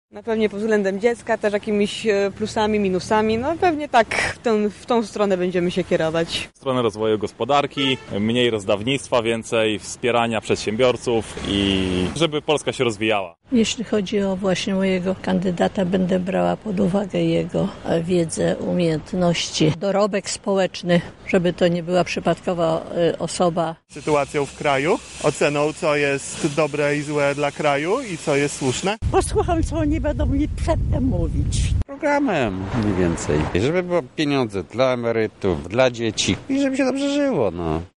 [SONDA]: Czym się kierujemy podczas wyborów parlamentarnych?
Zapytaliśmy więc Lublinian, czym będą się kierować przy stawianiu krzyżyka na karcie do głosowania obok swojego kandydata.